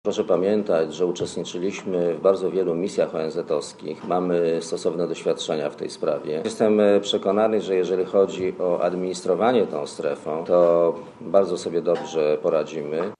Dla Radia Zet mówi premier Leszek Miller (103 KB)